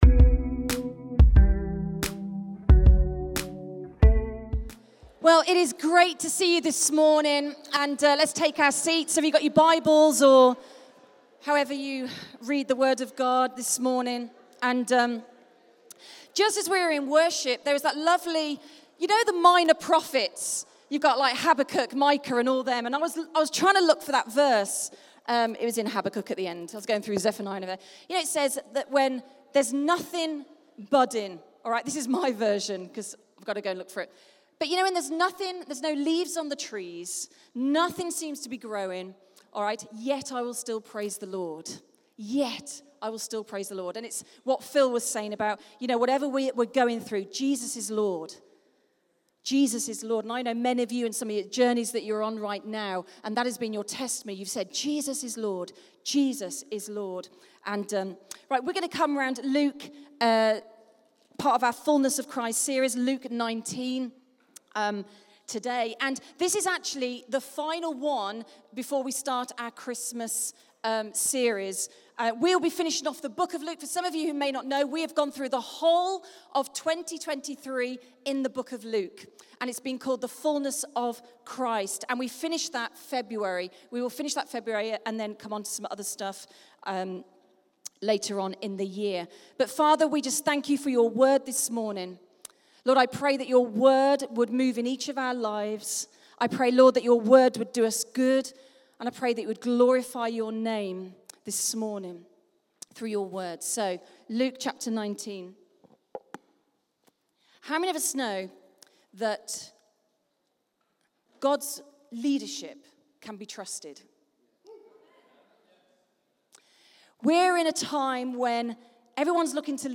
Rediscover Church Newton Abbot | Sunday Messages | The Fullness of Christ - Part 33